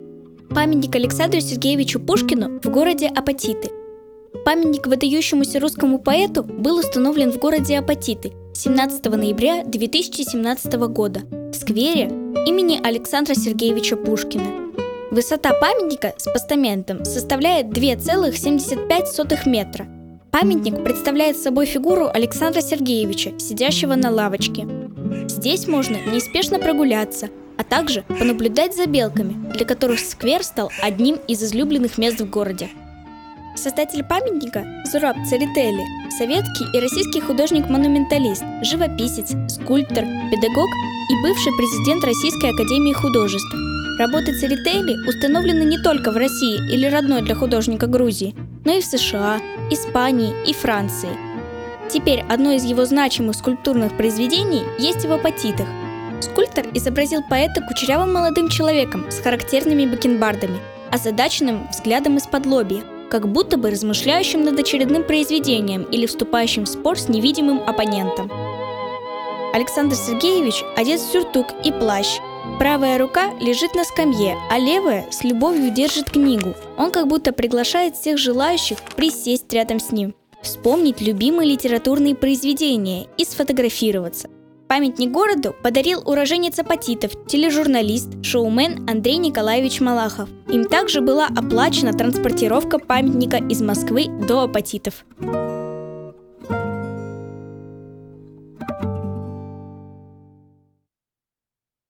Аудиоэкскурсия Памятник А. С. Пушкину